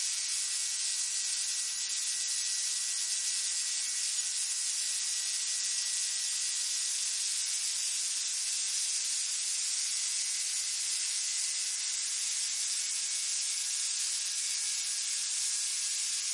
随机的 " 电动模糊电源线宽
描述：电动模糊电力线wide.flac
Tag: 模糊 电力线